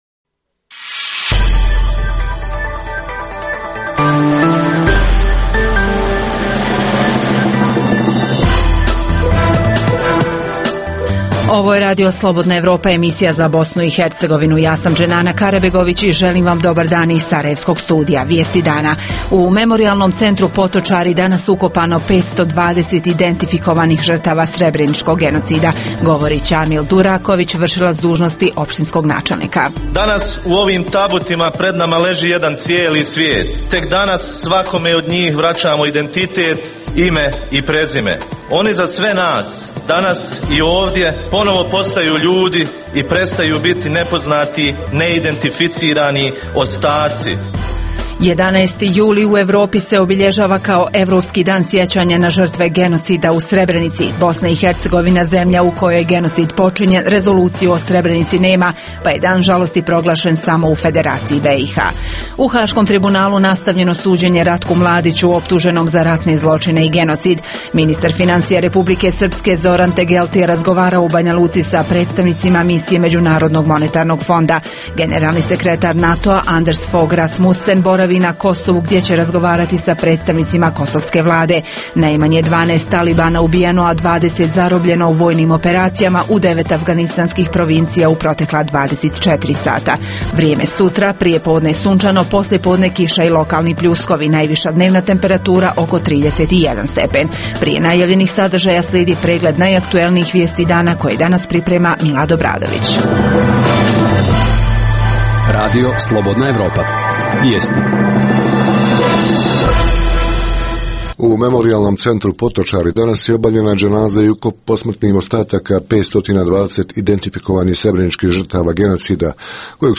Emisija je posvećena manifestacijama kojima se obilježava 17. godišnjica genocida u Srebrenici. Naši reporteri javljaju se iz Potočara, odakle prenose vjerski obred i ukop 520 identifikovanih Bošnjaka.